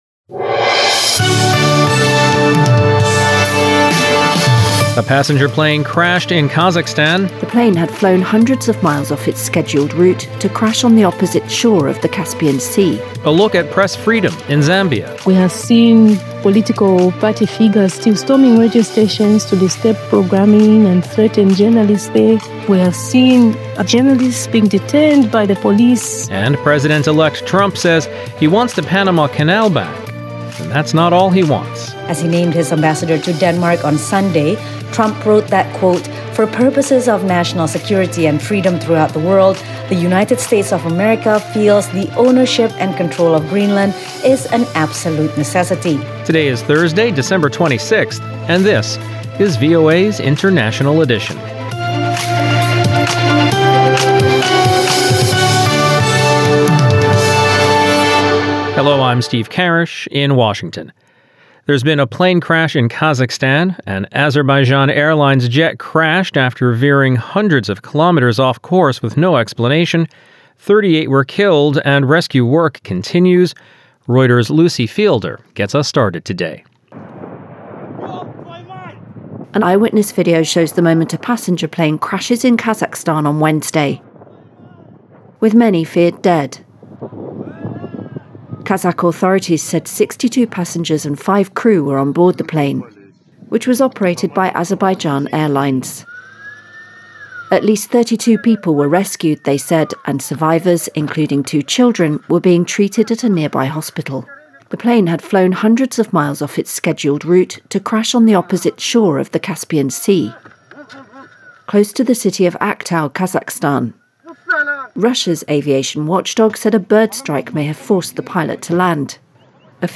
International Edition is VOA's premier global news podcast. Immerse yourself in the latest world events as we provide unparalleled insights through eye-witness accounts, correspondent reports, and expert analysis.